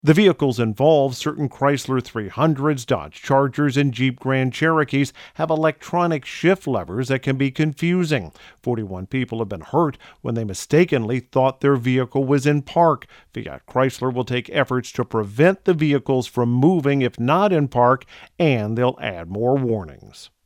AutoBeat reporter